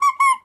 squeaky_rubber_toy_cartoon_02.wav